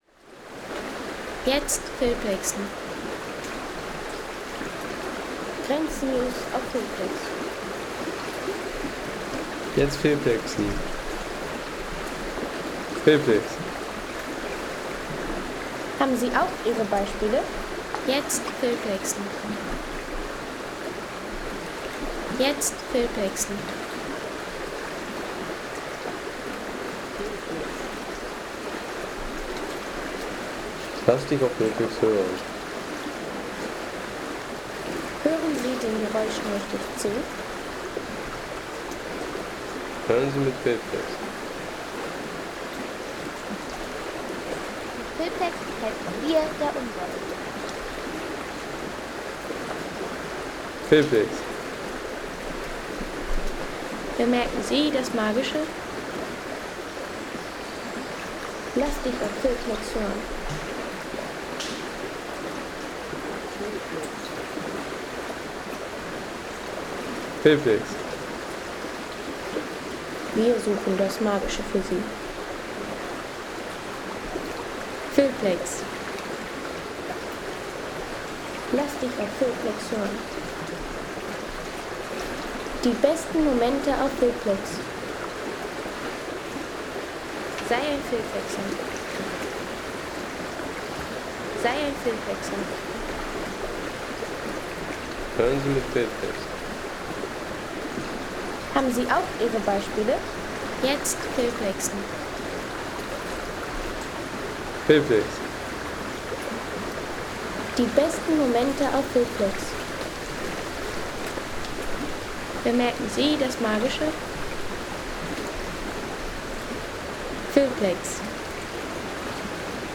Der Elzbach in Rheinland-Pfalz
Kategorien: Landschaft - Bäche/Seen
Elzbachtal – Natur pur auf dem Weg zur Burg Eltz.